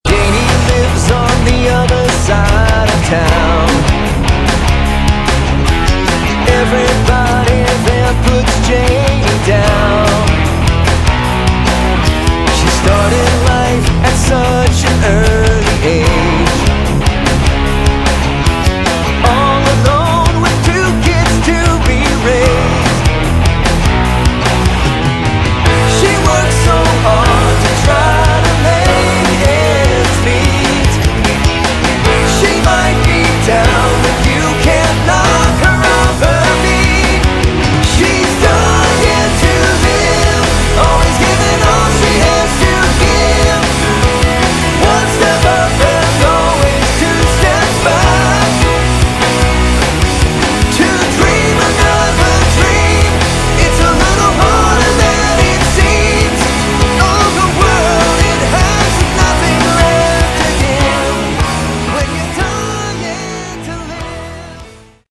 Category: Melodic Rock / AOR
vocals